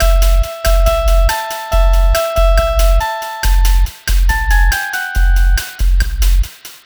Cheese Lik 140-A Hi.wav